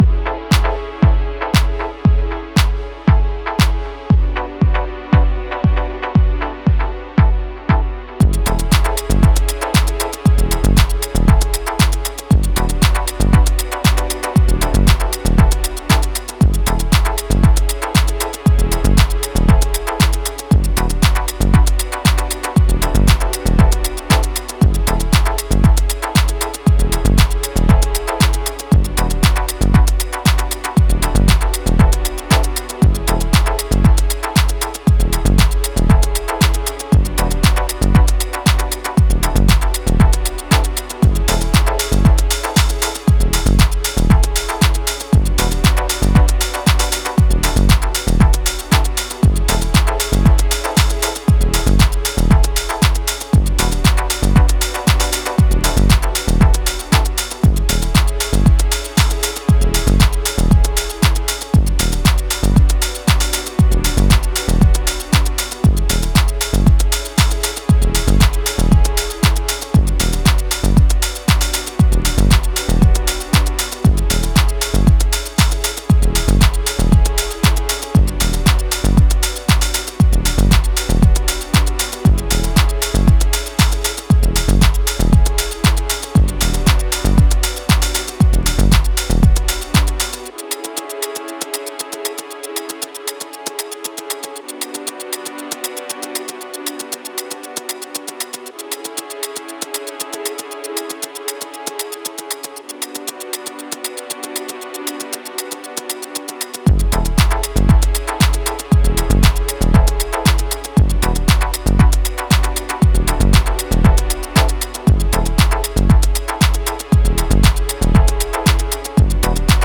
deep collection of House Music